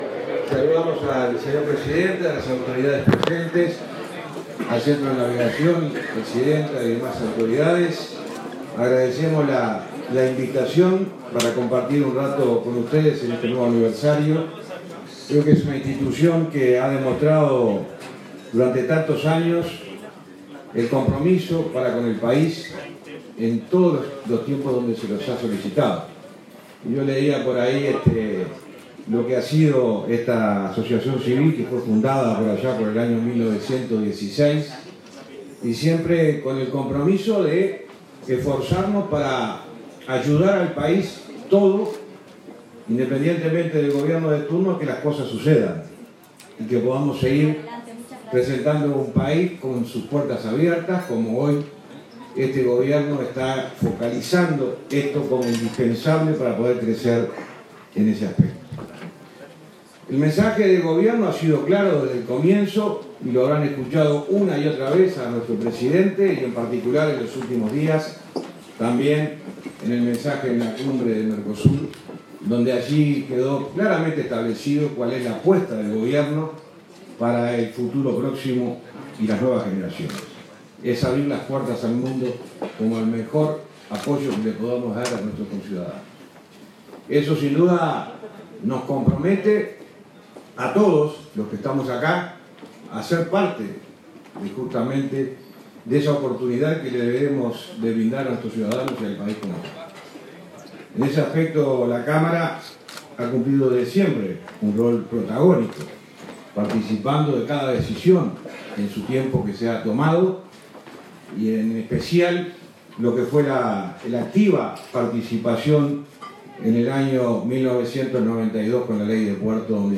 Falero participó en la ceremonia del 106º Aniversario del Centro de Navegación, que se celebró este jueves y que contó con la presencia también del presidente de la República, Luis Lacalle Pou, quien en esta oportunidad no hizo uso de la palabra.